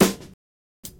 Huge Snare Sound B Key 13.wav
Royality free acoustic snare sample tuned to the B note. Loudest frequency: 1972Hz
huge-snare-sound-b-key-13-qLB.ogg